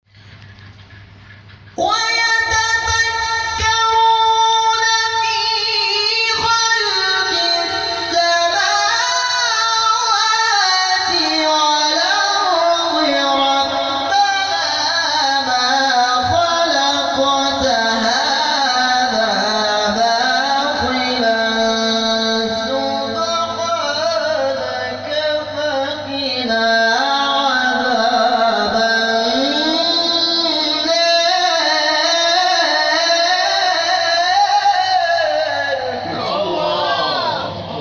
حساسیت بر آموزش ترکیبات لحنی در جلسه هفتگی بیت‌القرآن